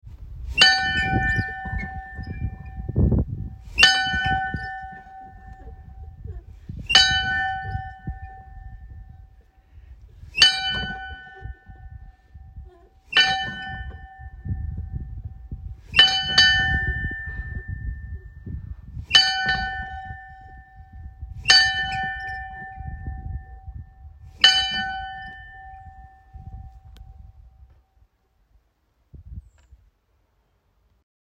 Hoffellskirkja - Kirkjuklukkur Íslands
Hoffellskirkja var byggð árið 1981. Ofan við dyr kirkjunnar er ein klukka sem virðist ómerkt.